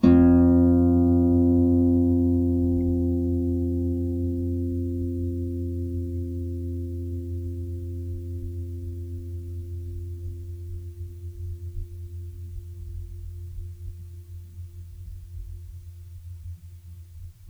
KSHarp_F2_mf.wav